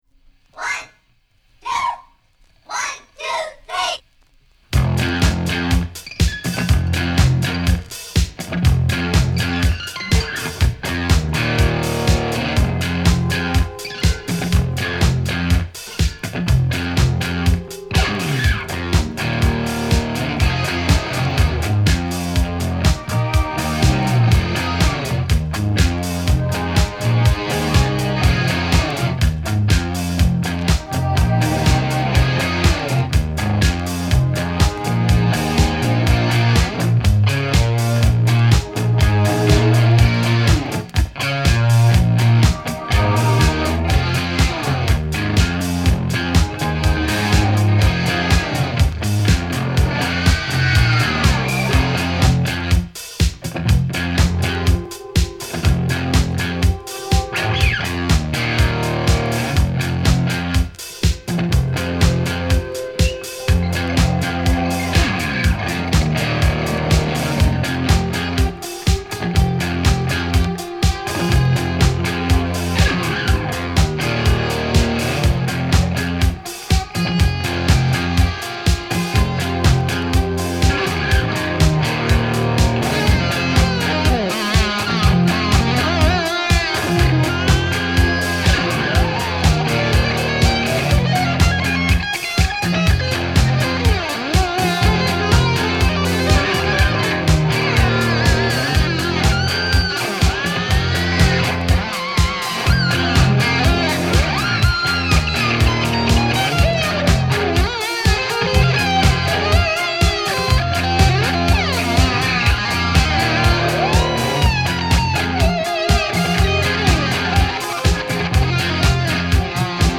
instrumental track